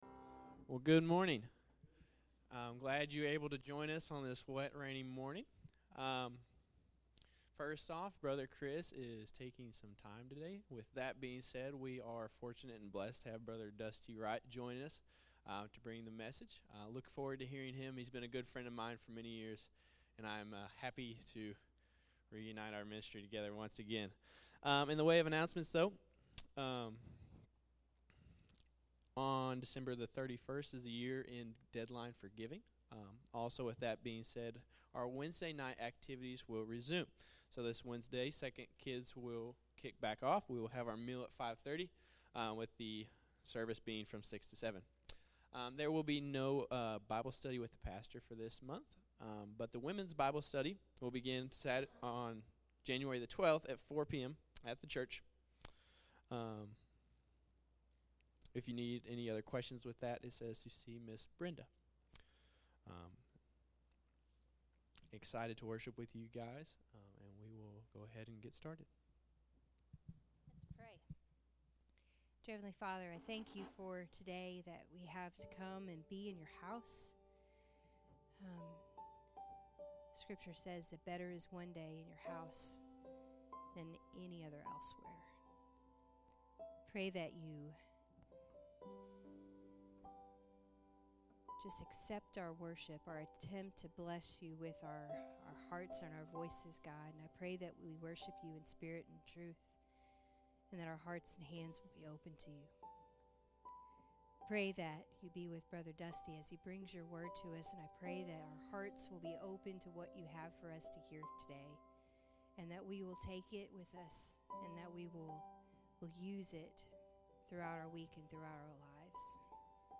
Sunday Morning Sermon January 5, 2025